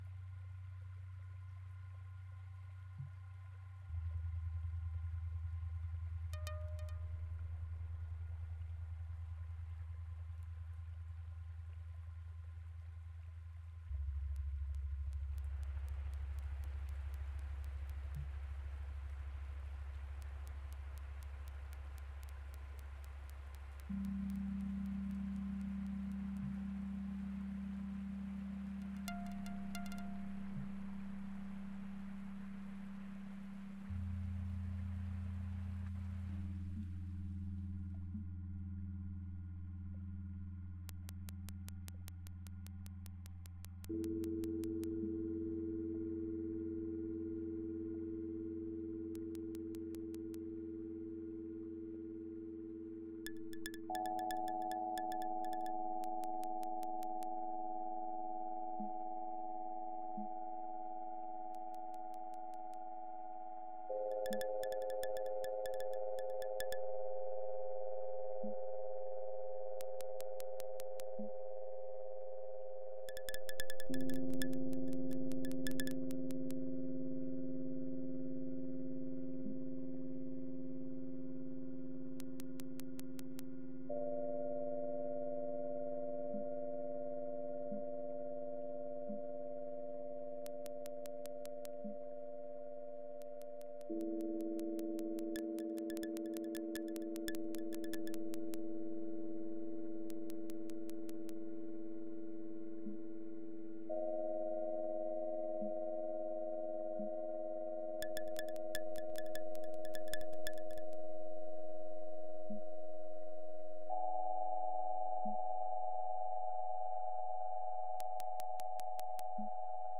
Eine grundsätzliche neue Art der Darstellung dieser Änderungssignale von Umweltdaten durch den Klimawandel wäre die algorithmische Aufbereitung der Daten in akustische Signale.
5) Demo Sounds 2024